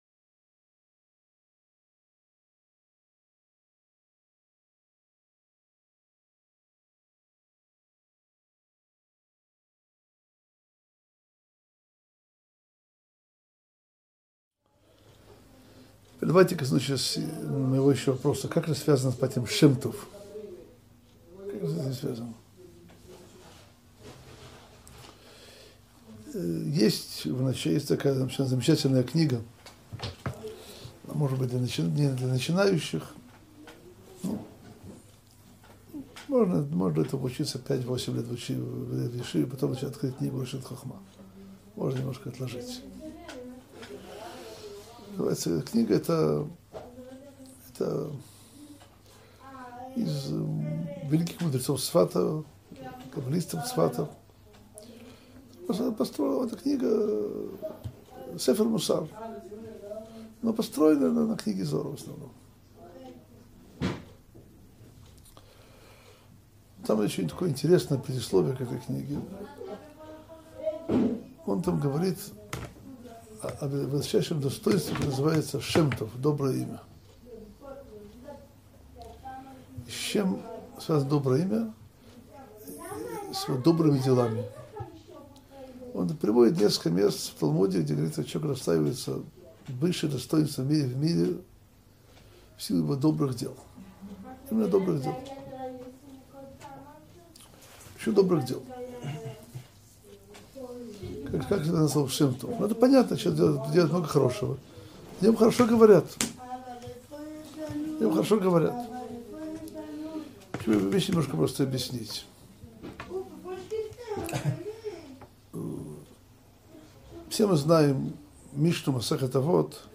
Уроки Торы - Ваякель - Роль Моше Рабейну - Сайт о Торе, иудаизме и евреях